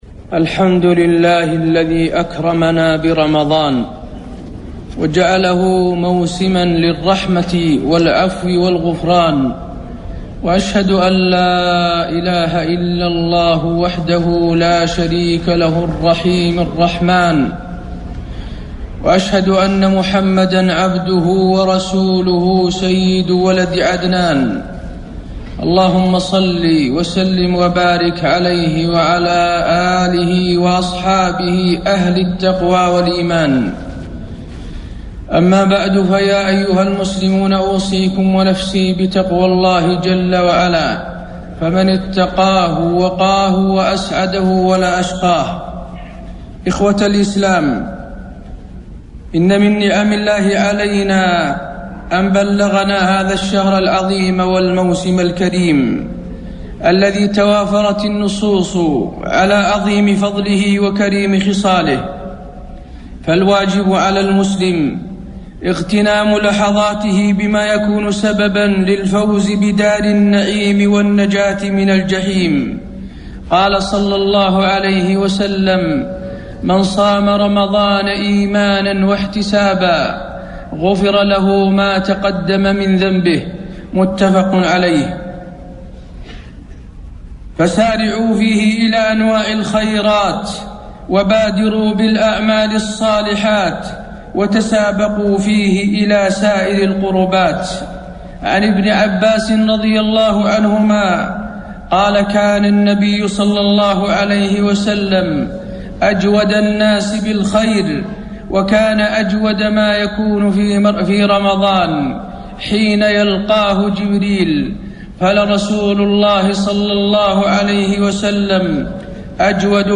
خطبة الجمعة 5-9-1432 هـ | موقع المسلم
خطب الحرم المكي